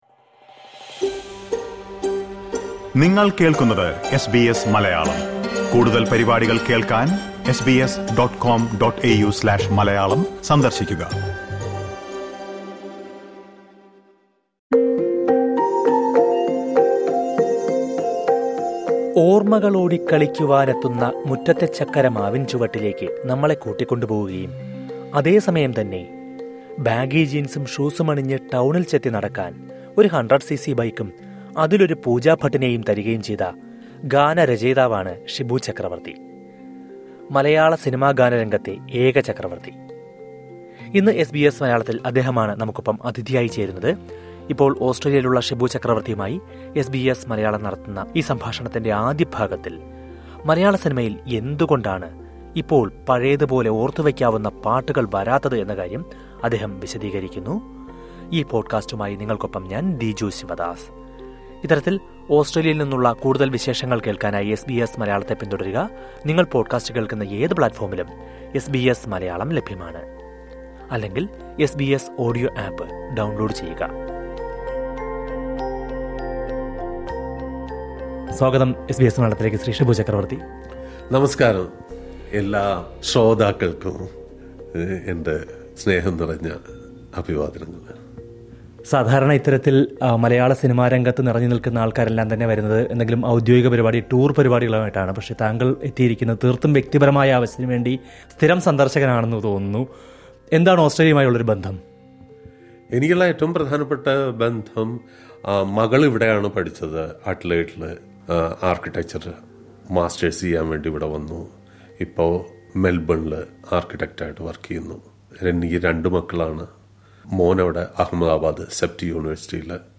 ഒരിക്കല്‍ മനംനിറയുന്ന പാട്ടുകളാല്‍ സമ്പുഷ്ടമായിരുന്ന മലയാള സിനിമയില്‍, ഇന്ന് ഓര്‍ത്തുവയ്ക്കാന്‍ കഴിയുന്ന പാട്ടുകള്‍ വിരളമാണ്. എന്തുകൊണ്ടാണ് ഈ മാറ്റം? മലയാള സിനിമാരംഗത്ത് നാലു പതിറ്റാണ്ട് പൂര്‍ത്തിയാക്കിയ ഗാനരചയിതാവ് ഷിബു ചക്രവര്‍ത്തി എസ് ബി എസ് മലയാളവുമായി സംസാരിക്കുന്നു.